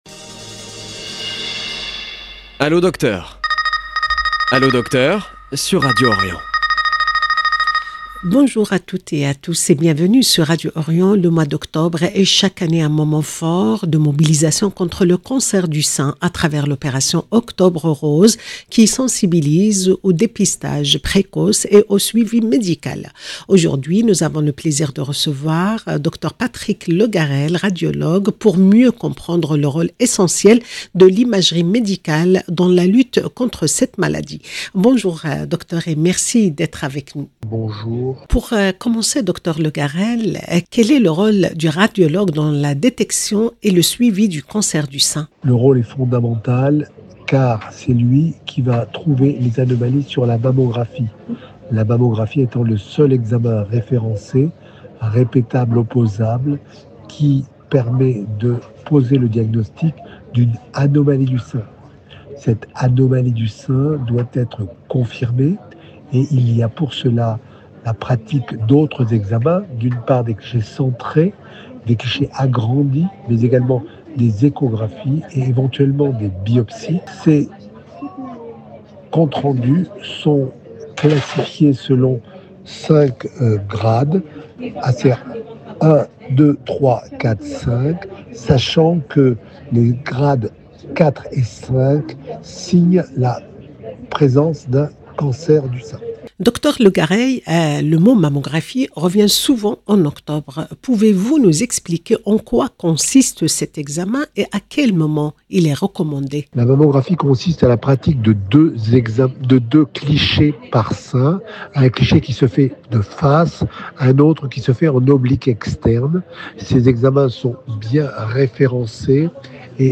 Ensemble, nous revenons sur l’importance de l’imagerie médicale – mammographie, échographie, IRM – dans le diagnostic précoce et le suivi du cancer du sein. Un échange essentiel pour mieux comprendre les enjeux de la prévention et les avancées technologiques qui sauvent des vies. 0:00 9 min 40 sec